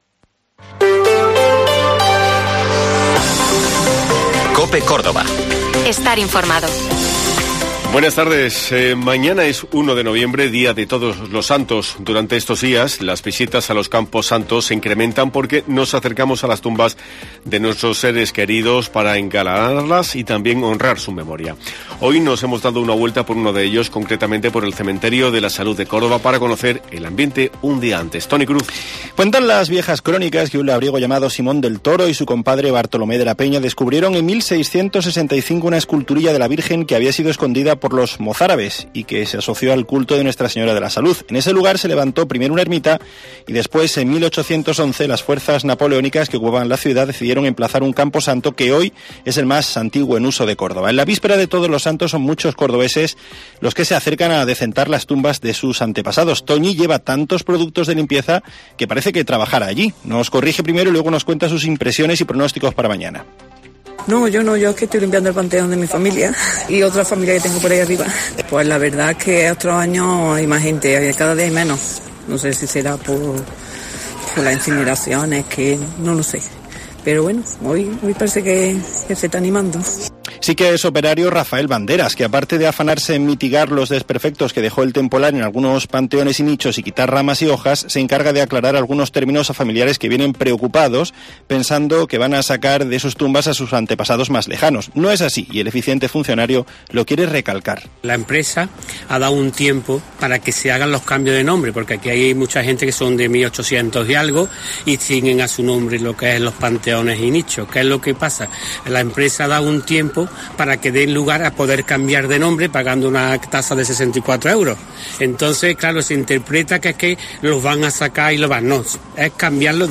Concretamente por el cementerio de la Salud de Córdoba, para conocer el ambiente un día.
Te contamos las últimas noticias de Córdoba y provincia con los reportajes que más te interesan y las mejores entrevistas.